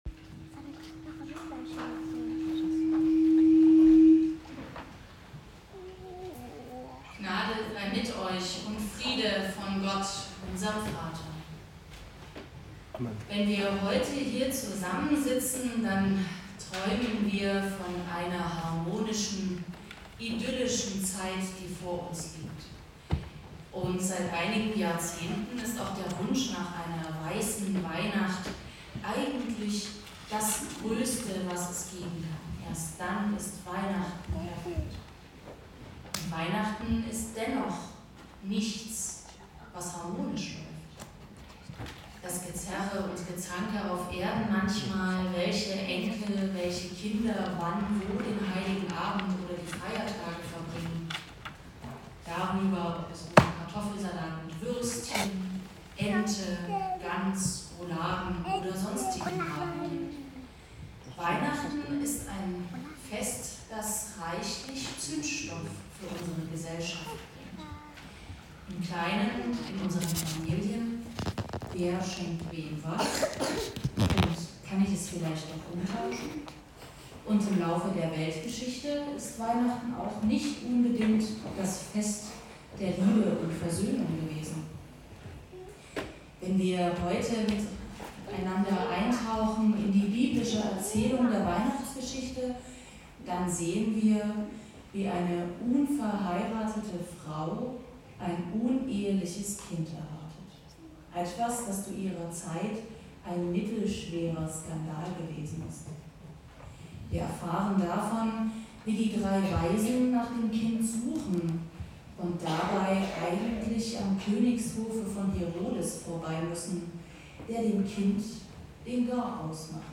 Gottesdienstart: Predigtgottesdienst